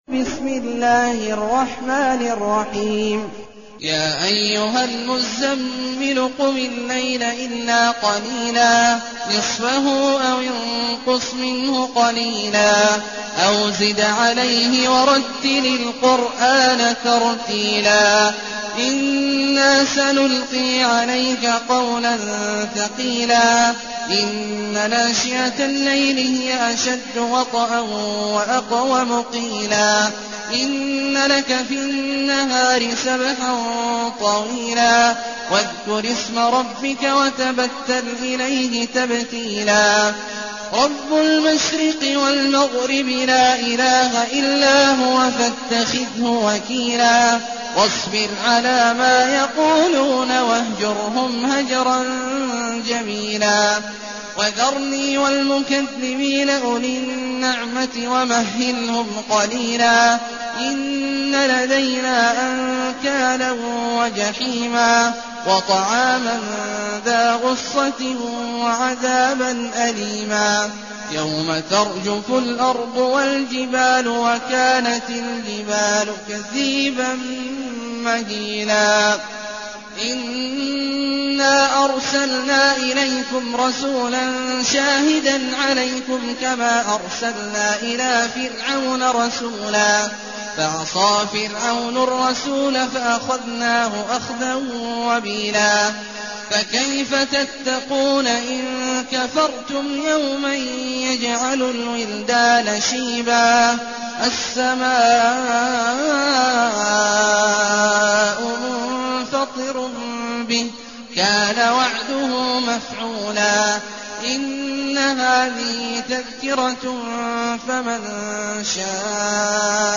المكان: المسجد النبوي الشيخ: فضيلة الشيخ عبدالله الجهني فضيلة الشيخ عبدالله الجهني المزمل